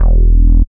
69.04 BASS.wav